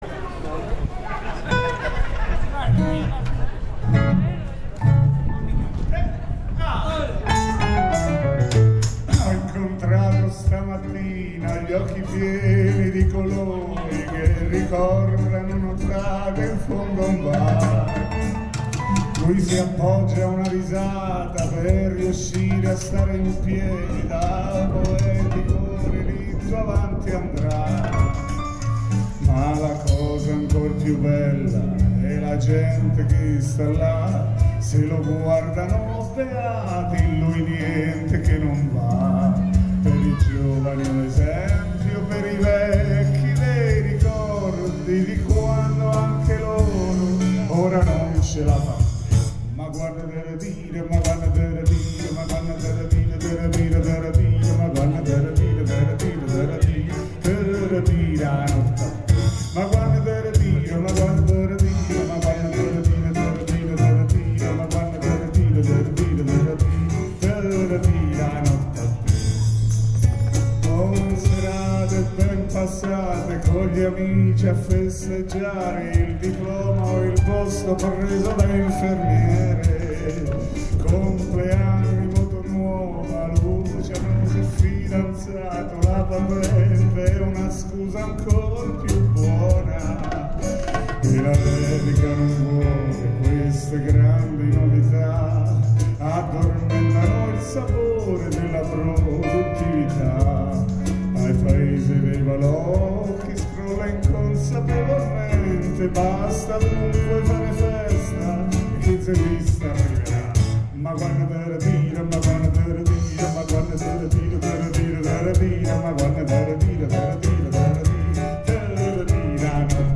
Anche in questo caso gambatesanews, oltre alle foto, ha avuto modo di registrare l’intero concerto, nato per la verità come presentazione del nuovo CD del gruppo folk di Riccia, disco che a dire di chi lo ha presentato, racchiude cent’anni di musica nata nella vicina cittadina e nei borghi circostanti, inutile retorica lasciata a sé stessa e non ripresa da chi queste forme di auto-celebrazione le aborrisce da sempre.
musica fondamentalmente triste, ma nello stesso tempo portatrice di speranza